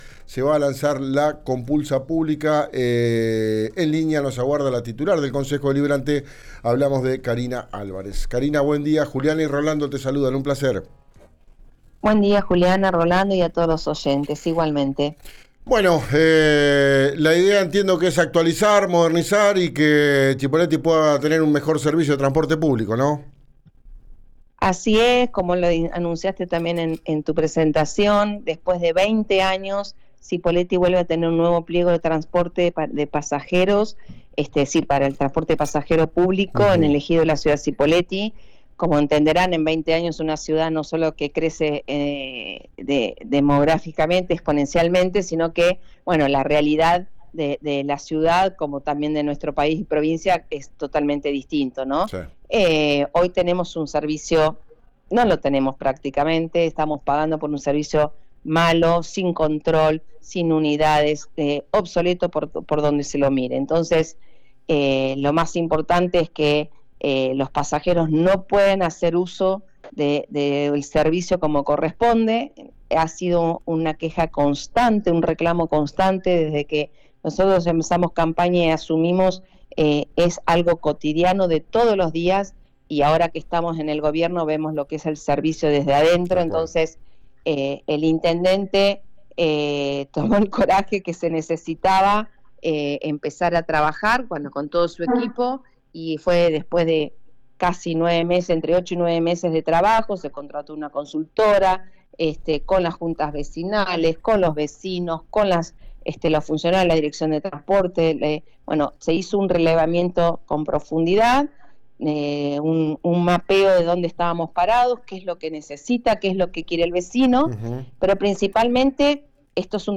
Escuchá a la titular del organismo, Karina Alvarez, en el aire de »Ya es Tiempo» por RÍO NEGRO RADIO: